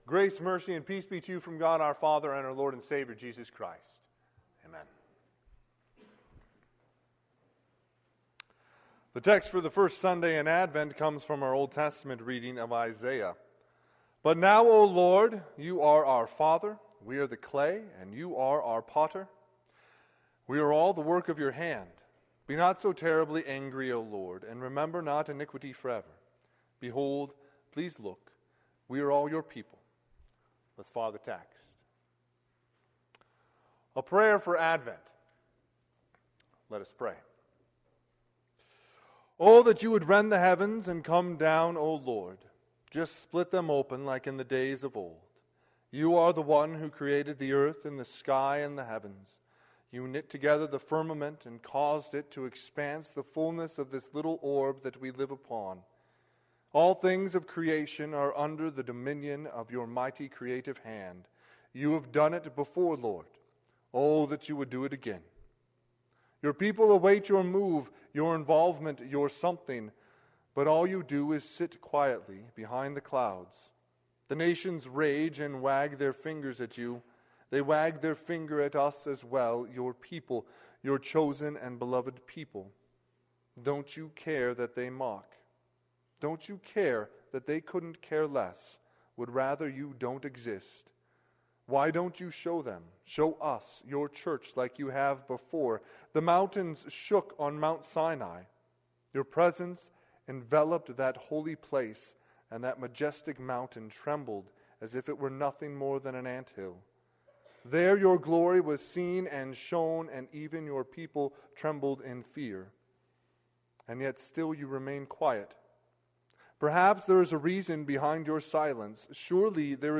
Sermon - 11/29/2020 - Wheat Ridge Lutheran Church, Wheat Ridge, Colorado
First Sunday of Advent